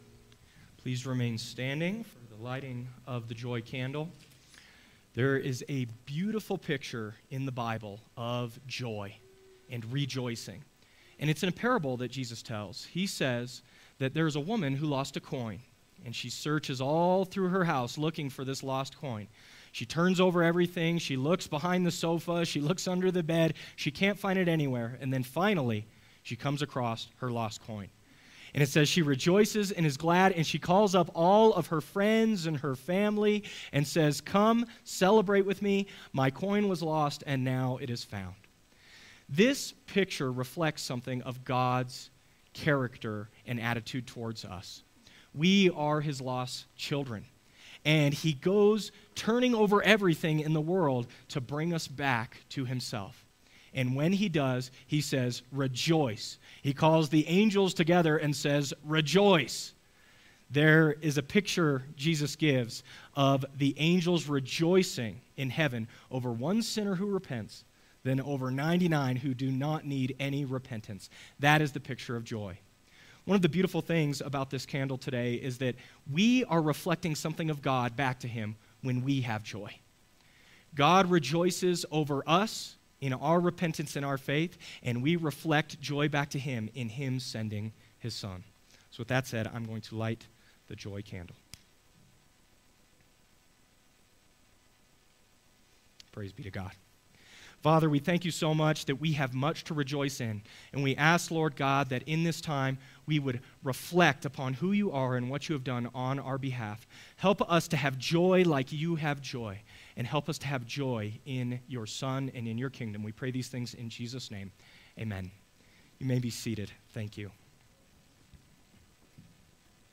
Sermons | First Baptist Church of Leadville